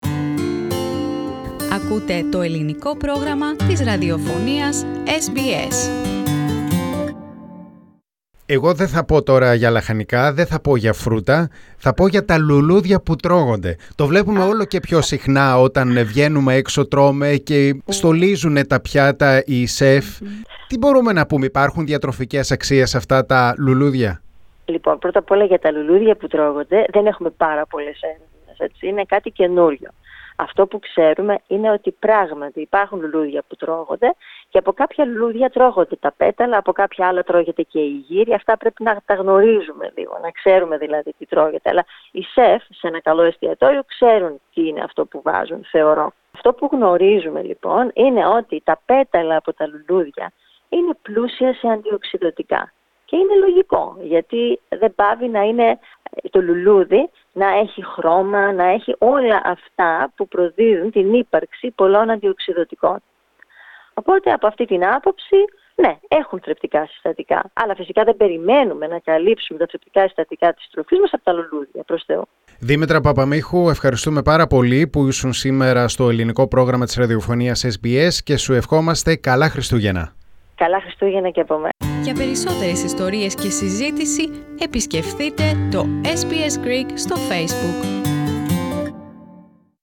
Πατήστε Play στην κεντρική φωτογραφία για να ακούσετε τη συνέντευξη